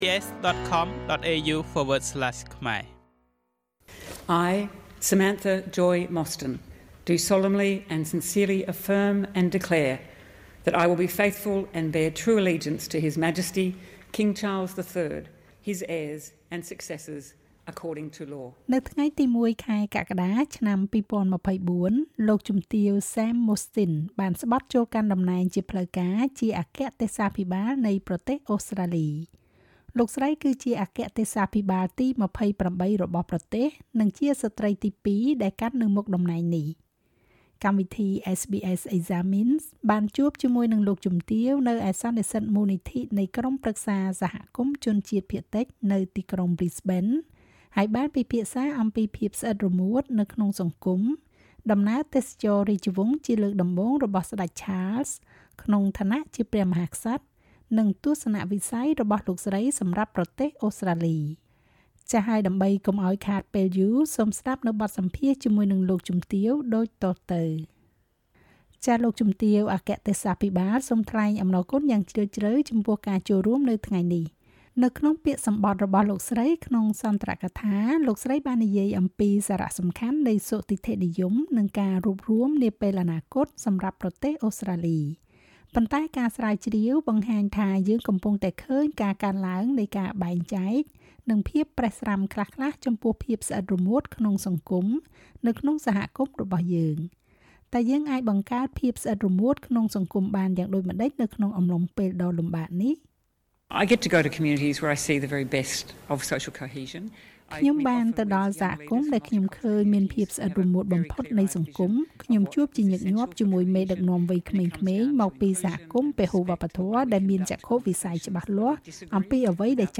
SBS Examines: បទសន្ទនាជាមួយលោកស្រីអគ្គទេសាភិបាលនៃប្រទេសអូស្រ្តាលី